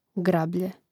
grȁblje grablje